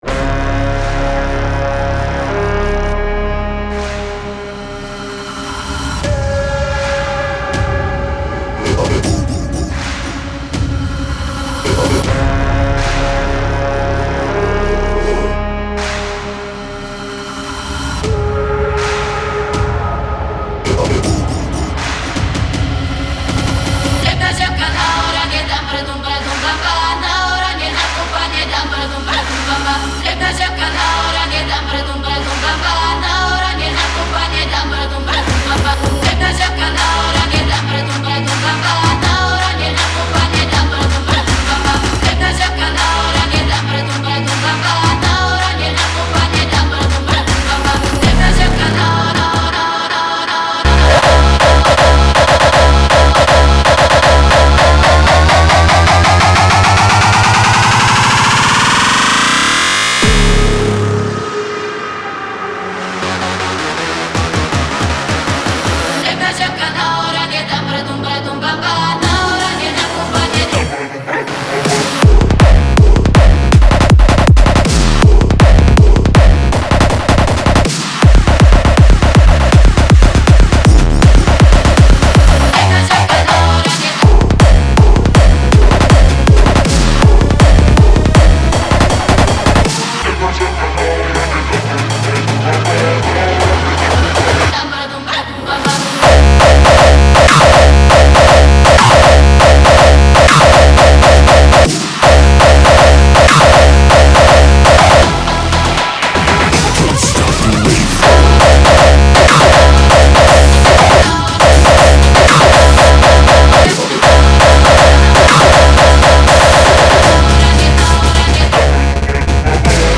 • Жанр: Techno, Hardstyle